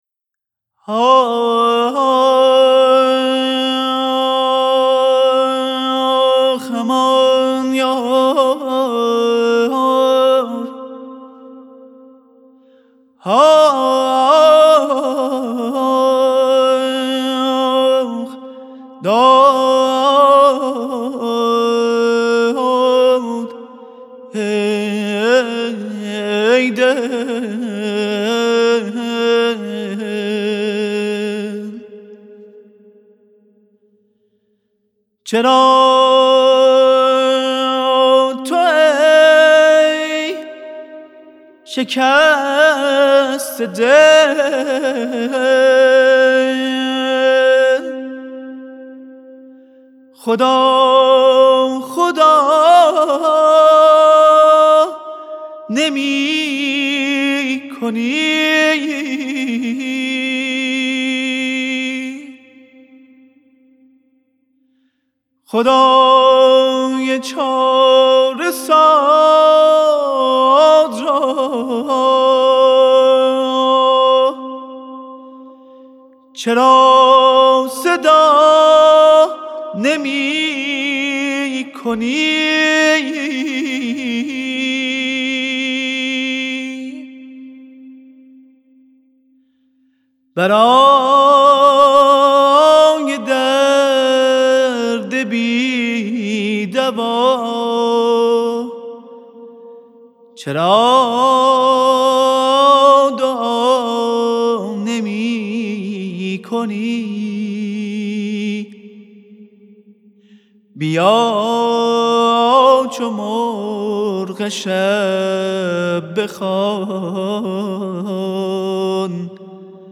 برگرفته از ردیف آوازی موسیقی ایرانی